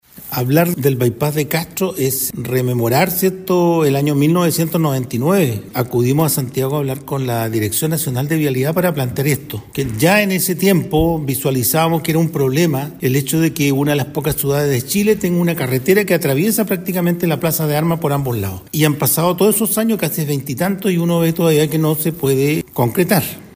El alcalde de esa época, Nelson Águila, y quien hoy se desempeña como consejero regional de Los Lagos, contó a Radio Bío Bío cómo nació esta anhelada iniciativa para los chilotes.